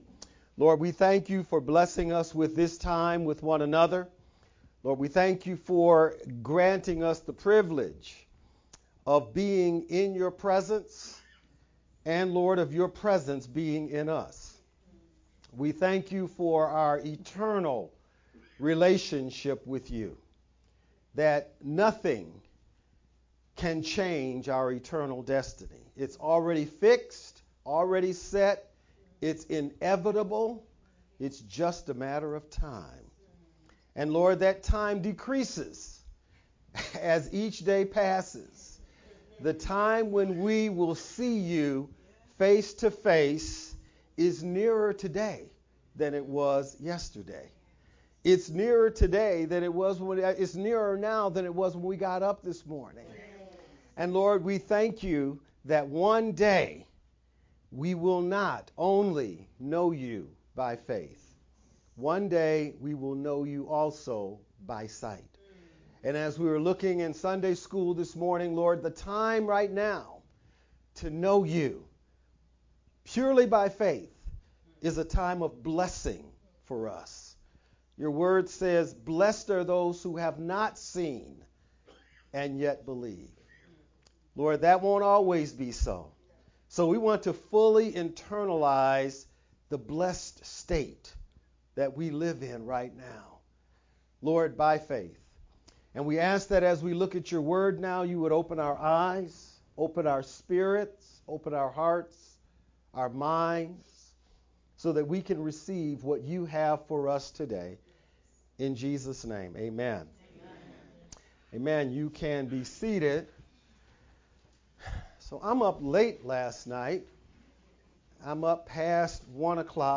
March-8th-VBCC-sermon-only-edited-CD.mp3